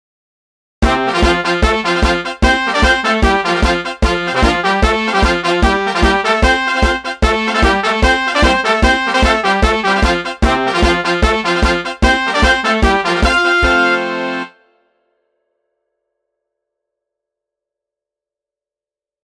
最初はゆっくりとしたノーマルものから、大部分の人ができるようになったときのレベルを上げてテンポを上げた音源も掲載。
150 Kintaro_temp150
Kintaro_temp150.mp3